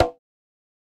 Kanye Perc.wav